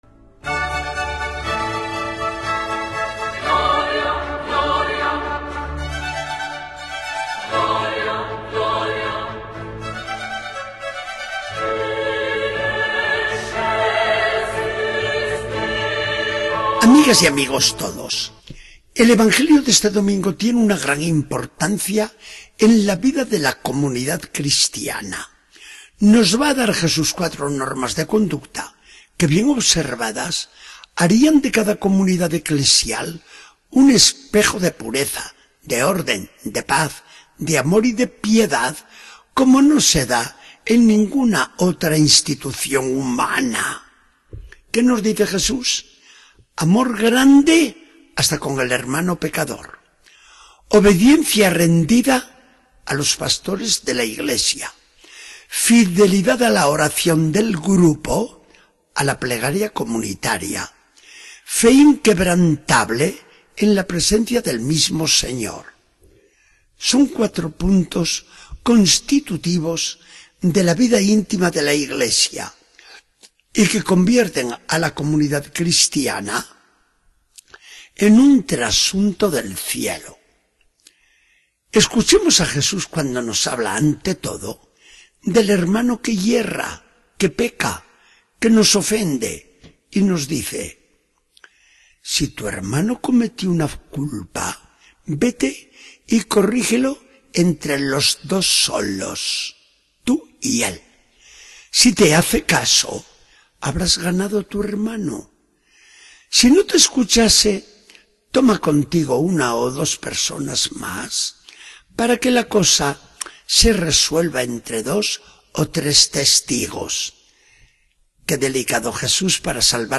Charla del día 7 de septiembre de 2014. Del Evangelio según San Mateo 18, 15-20.